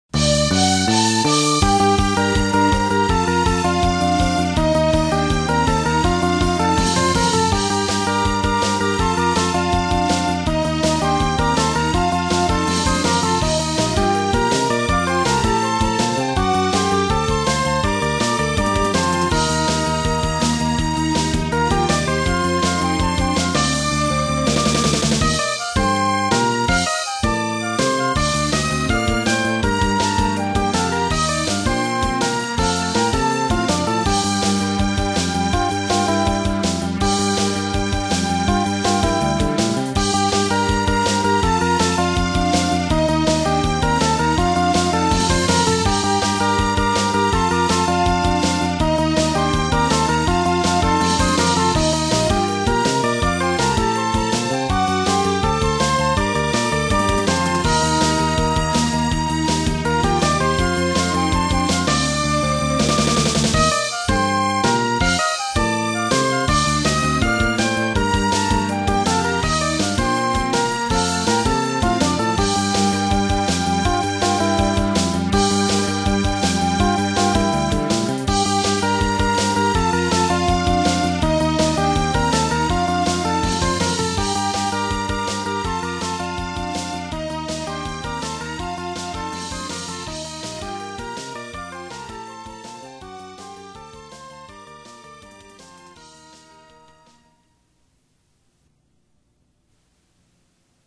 で、ミク買ったはいいけど、しばらくDTMやってなくてブランクあったから、昨今のDAWとかさっぱりわからん中、所持してるmotif ESに付属のSQ1使って試行錯誤しながら作ったのがこの曲ですｗ
音源：motif ES7 & 初音ミク